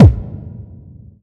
Index of /90_sSampleCDs/Club_Techno/Percussion/Kick
Kick_16.wav